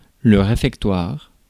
Prononciation
Synonymes popote cuistance Prononciation France Accent inconnu: IPA: /ʁe.fɛk.twaʁ/ Le mot recherché trouvé avec ces langues de source: français Traduction 1.